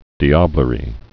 (dēblə-rē, -ăblə-)